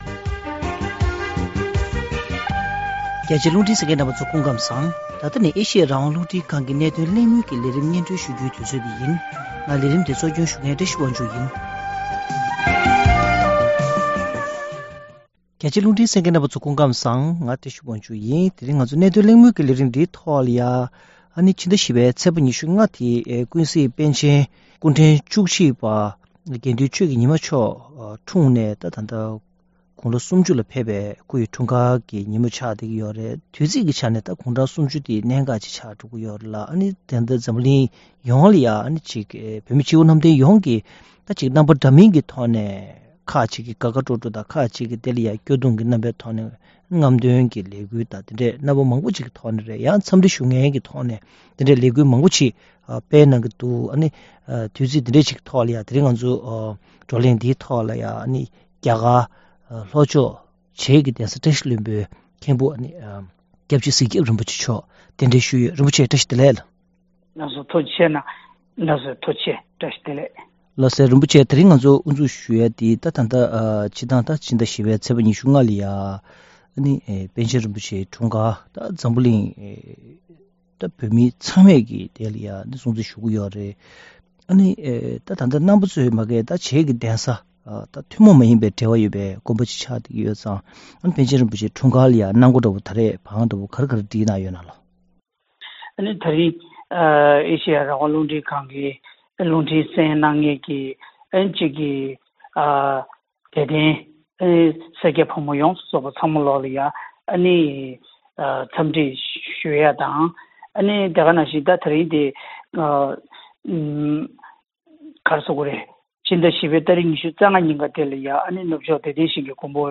བཀའ་འདྲི་གླེང་མོལ་ཞུས་པའི་ལས་རིམ་ལ་གསན་རོགས་ཞུ།